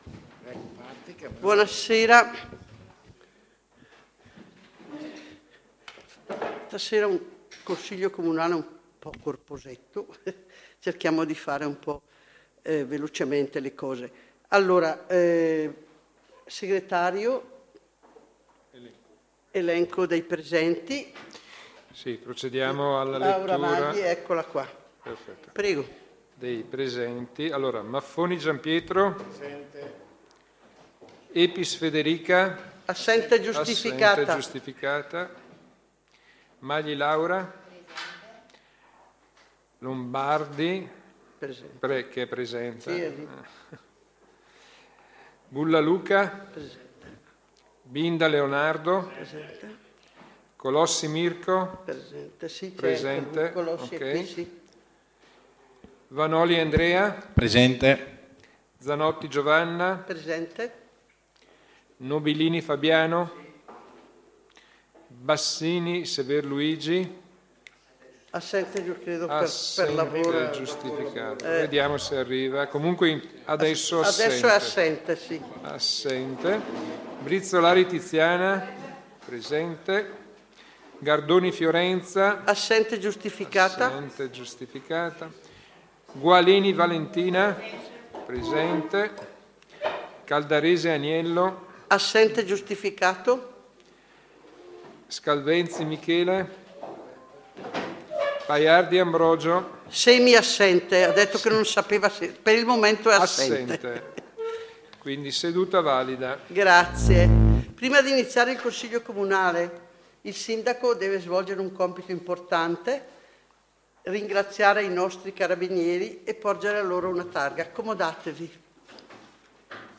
Seduta Consiglio Comunale del 18 dicembre 2023